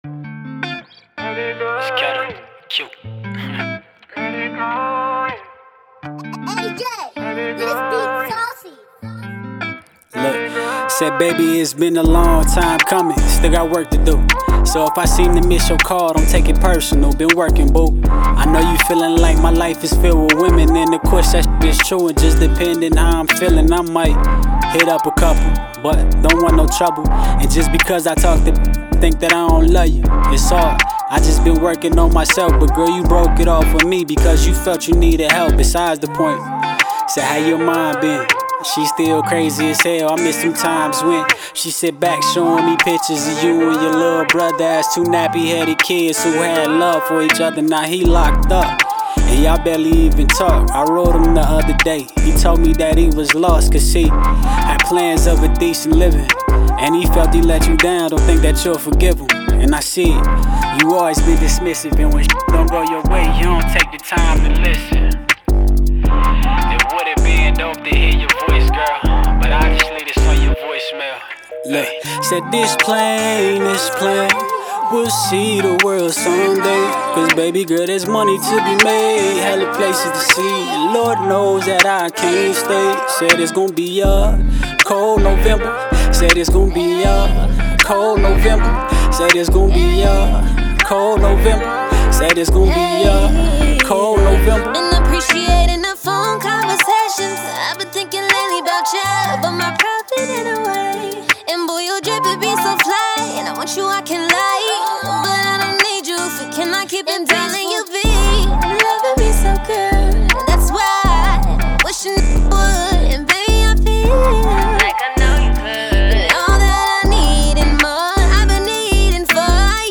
Hiphop
is a vibrant and emotive track